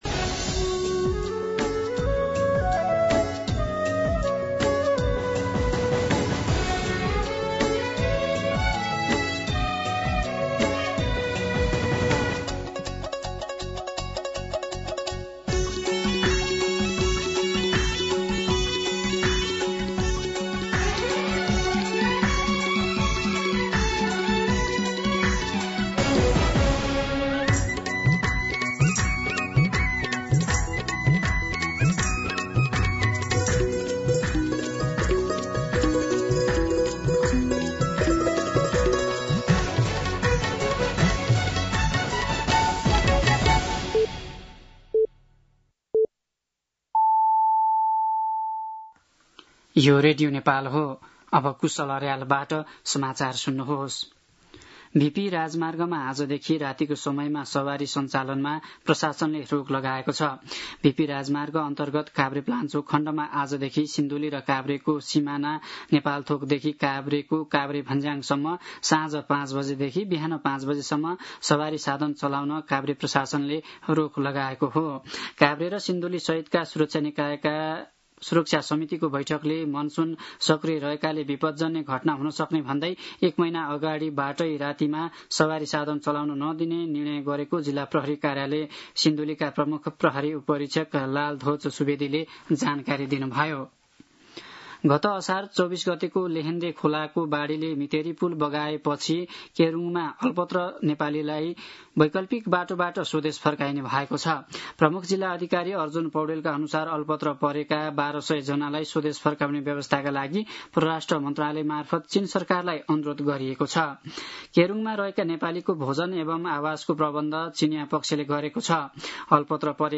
दिउँसो ४ बजेको नेपाली समाचार : १ साउन , २०८२